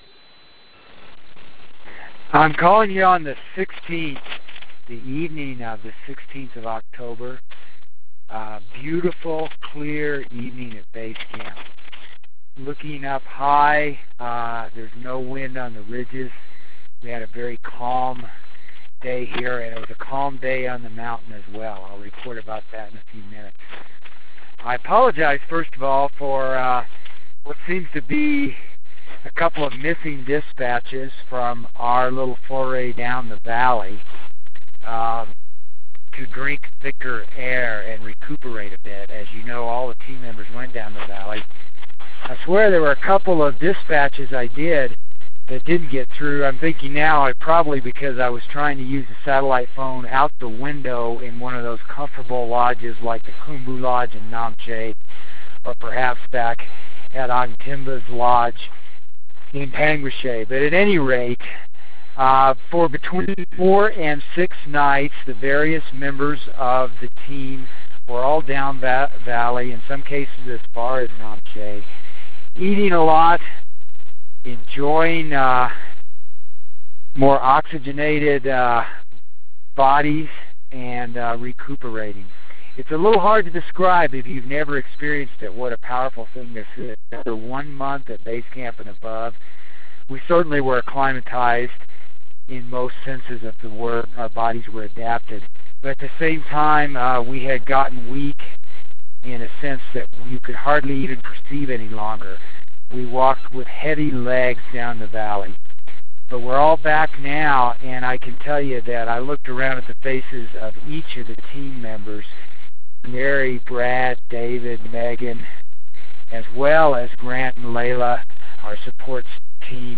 October 16 - Regrouping and refocusing at Base Camp